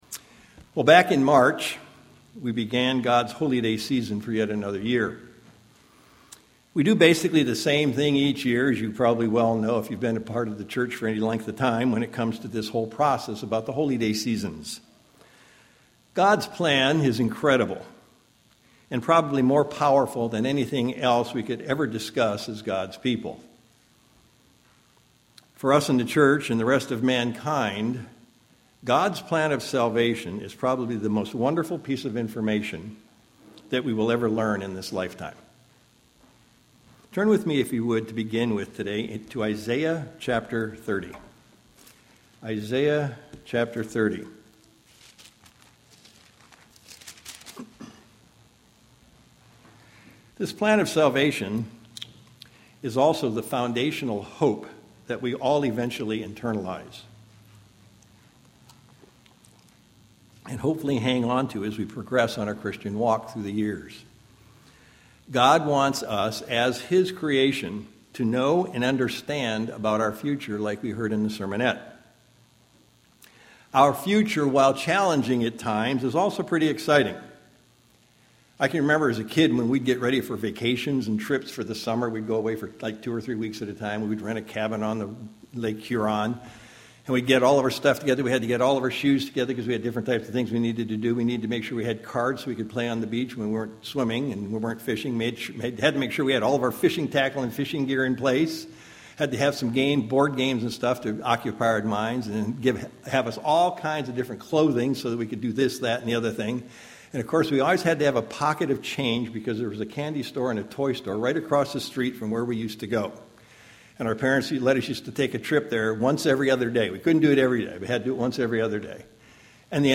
Sermons
Given in Sacramento, CA